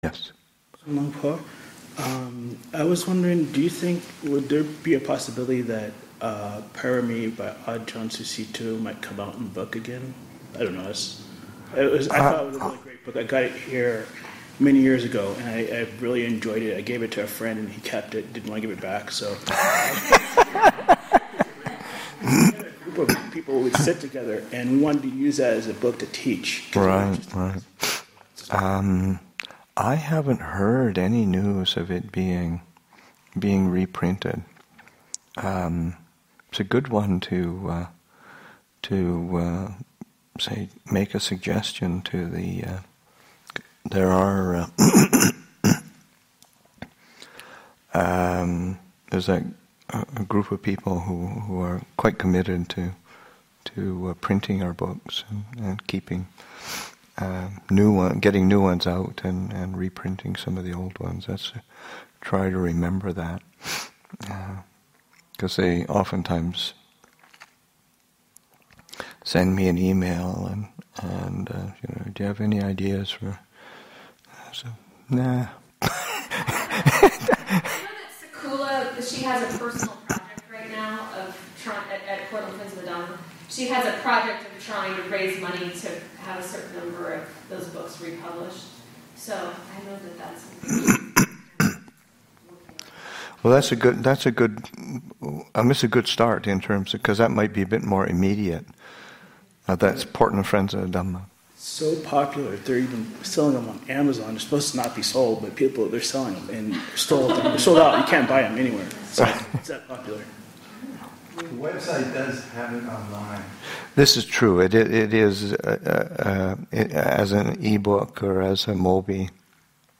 Abhayagiri Buddhist Monastery in Redwood Valley, California and online